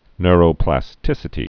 (nrō-plăs-tĭsĭ-tē, nyr-)